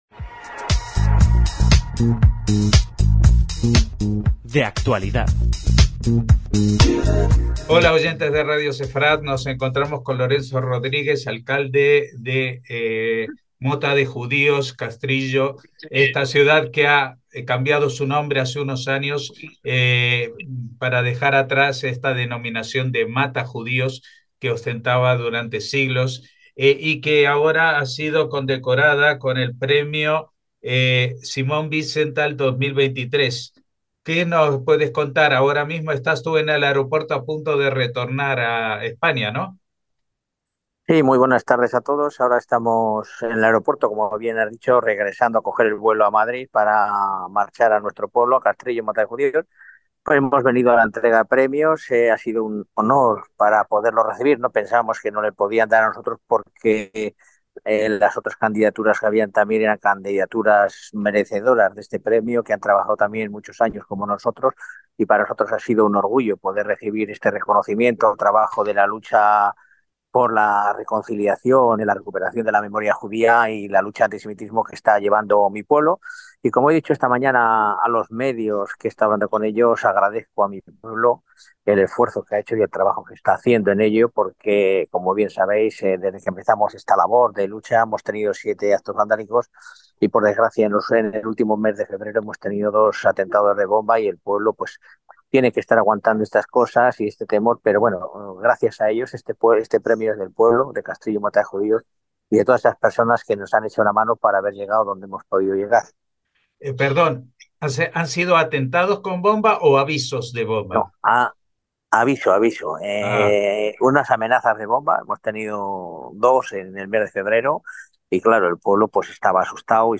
DE ACTUALIDAD - Casi con un pie en la escalerilla del avión que lo trae a España desde Viena, pudimos hablar con el alcalde de Castrillo Mota de Judíos, Lorenzo Rodríguez, localidad que ha sido premiada por su esfuerzo de reconciliación con su pasado judío con el galardón que lleva el nombre del ilustre "cazanazis" Simon Wiesenthal. Recordemos que el pequeño pueblo burgalés ha venido sufriendo ataques desde su decisión de corregir su nombre de "Matajudíos" por el original de "Mota de Judíos" desde que los vecinos votaron hacerlo hace casi una década, lo que les ha llevado a sufrir muchos incidentes antijudíos, por ejemplo dos amenazas de bomba el pasado mes de febrero.